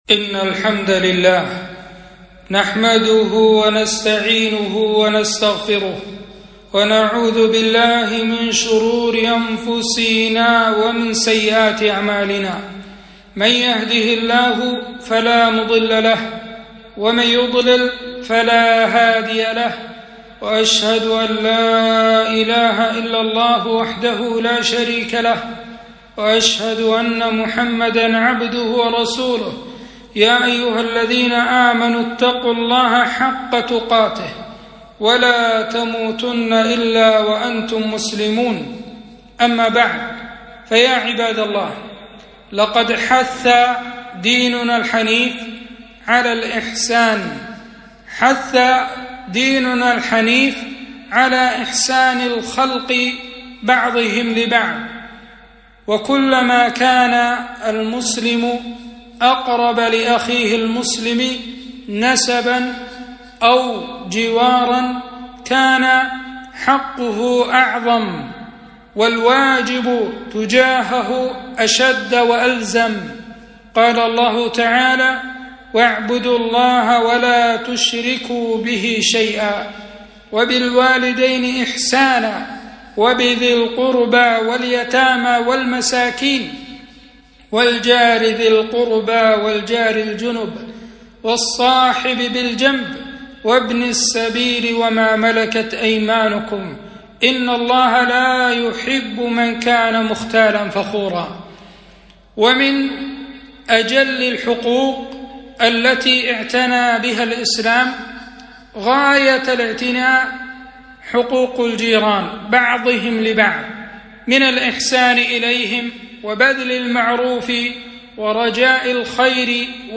خطبة - فضائل إكرام الجار والتحذير من آذاه